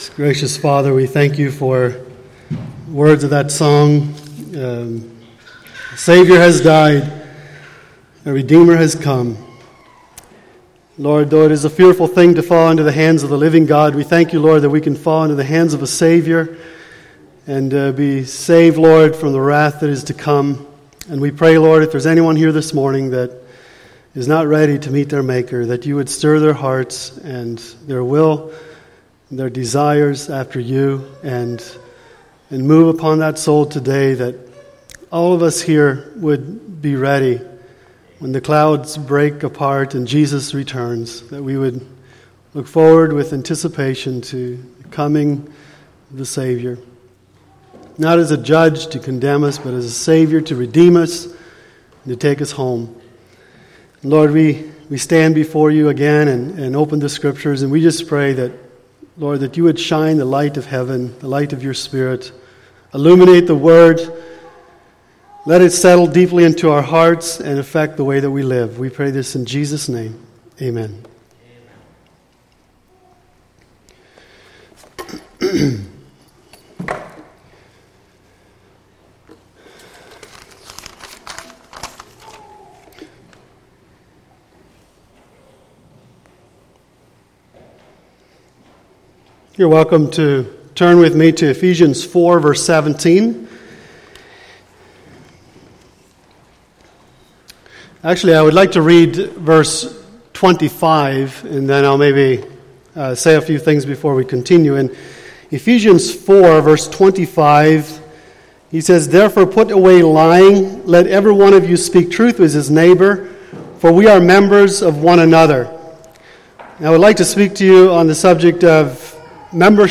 Sunday Morning Sermon Service Type: Sunday Morning